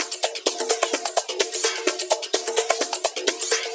VEH1 Fx Loops 128 BPM
VEH1 FX Loop - 07.wav